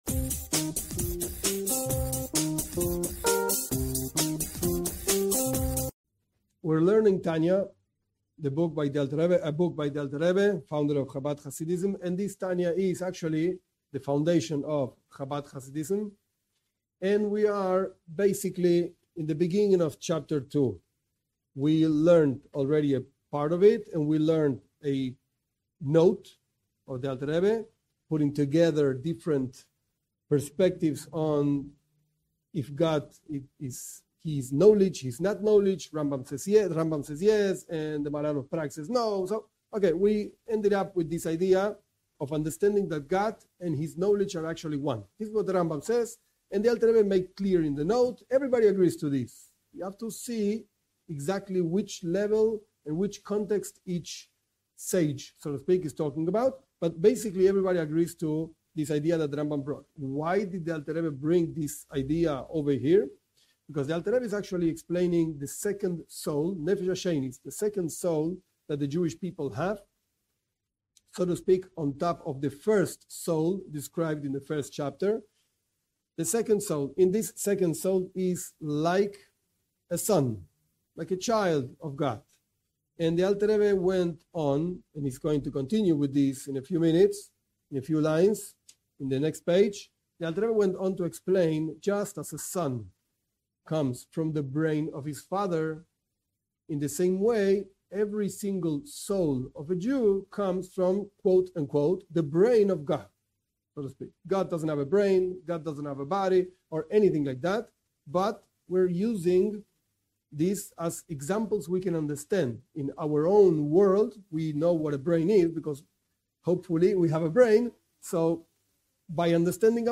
This class teaches Tanya, Book of Beinonim (intermediate people).